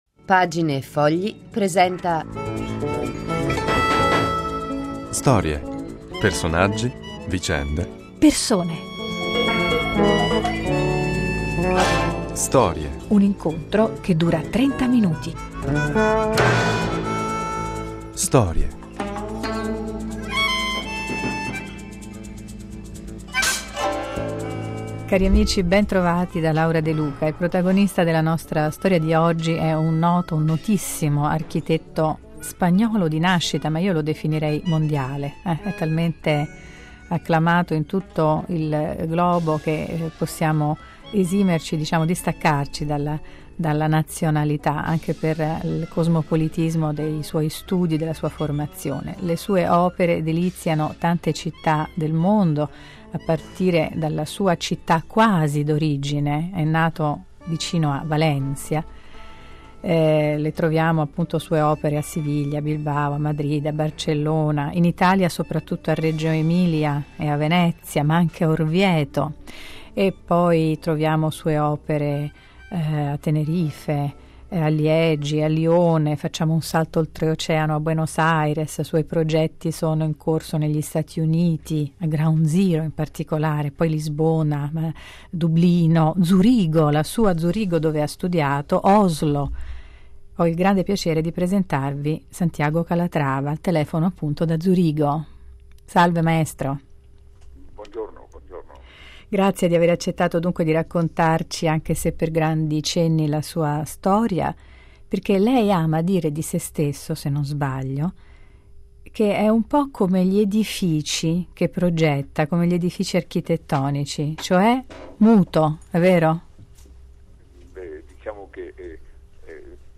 si racconta al microfono